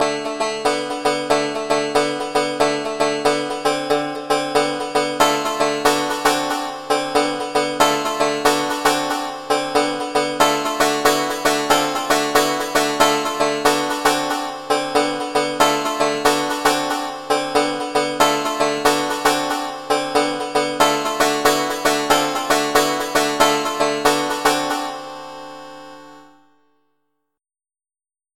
channel 3 is patch 105, which is a banjo
Then a couple of echoes on the same channel (3) were added with